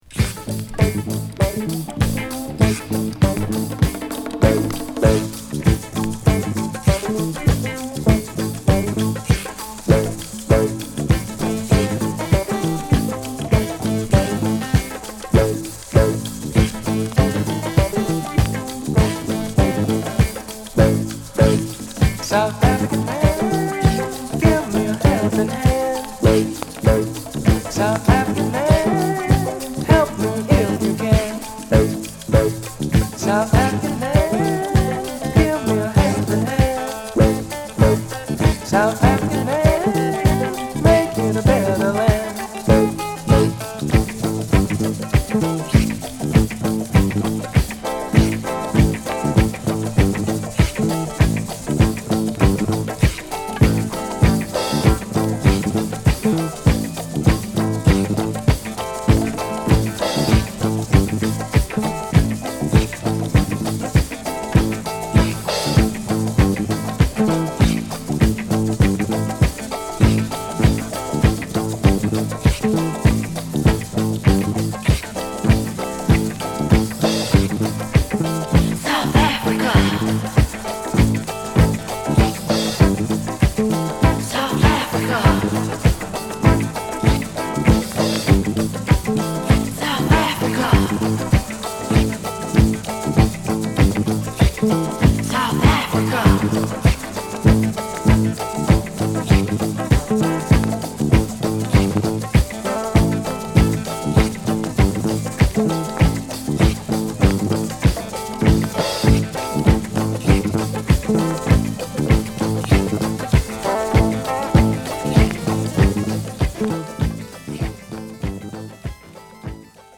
*終盤数回転ノイズ有